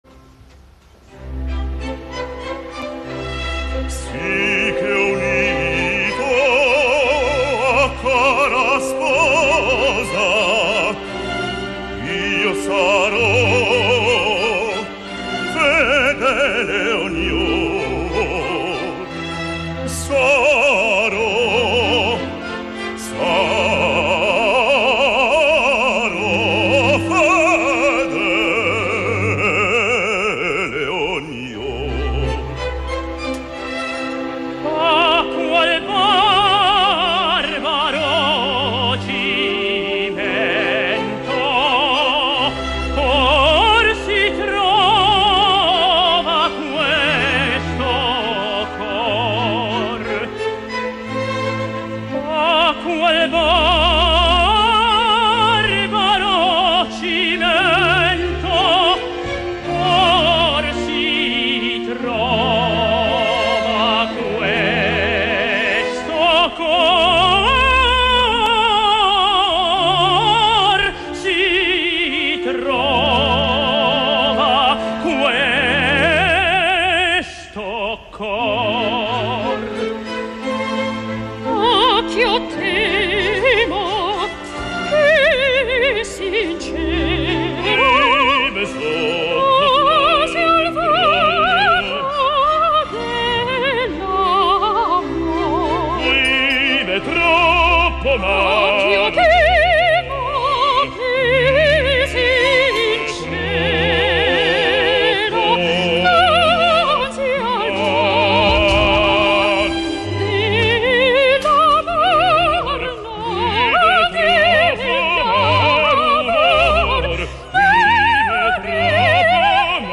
Tot i així la direcció és briosa i lleugera com correspon a aquesta farsa.
Per acabar els tasts, us deixo amb el quartet “Si che unitoa cara sposa”
Orchestra Sinfonica G. Rossini